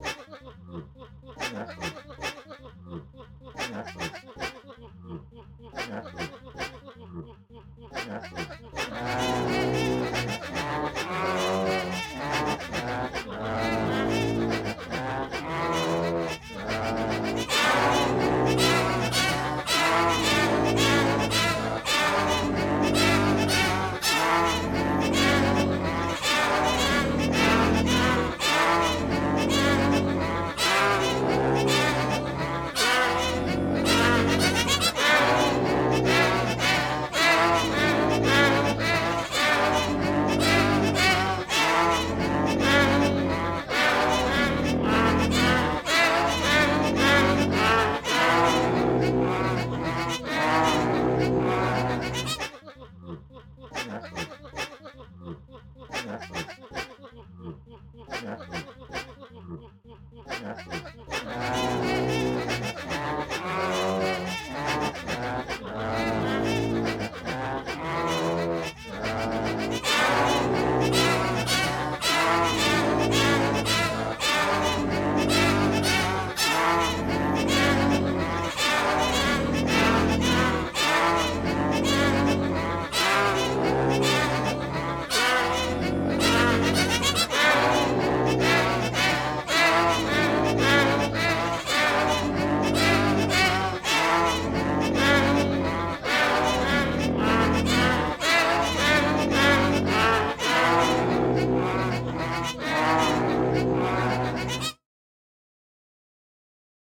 Meow